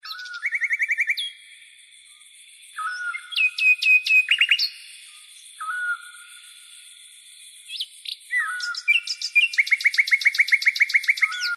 Птицы в лесу